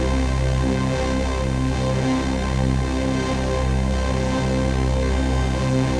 Index of /musicradar/dystopian-drone-samples/Non Tempo Loops
DD_LoopDrone5-C.wav